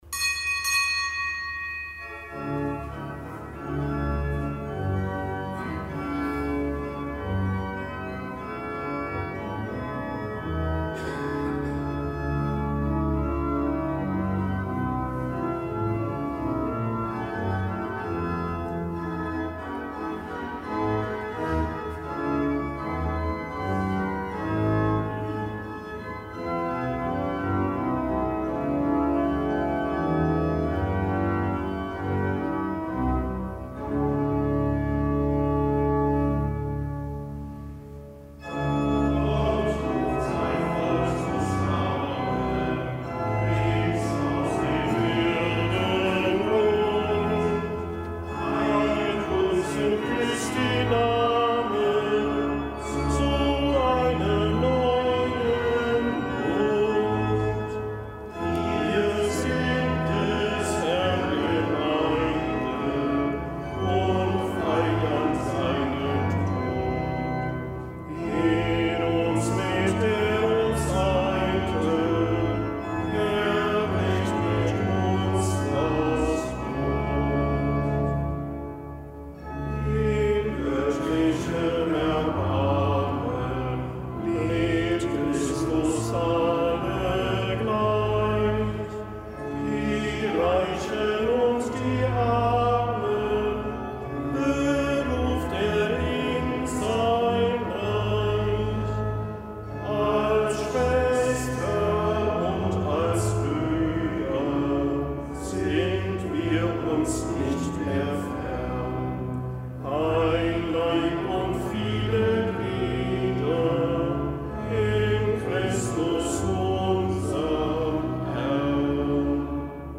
Kapitelsmesse am Gedenktag des Heiligen Paul Miki und Gefährten
Kapitelsmesse aus dem Kölner am Gedenktag des Heiligen Paul Miki und Gefährten, Märtyrer in Nagasaki.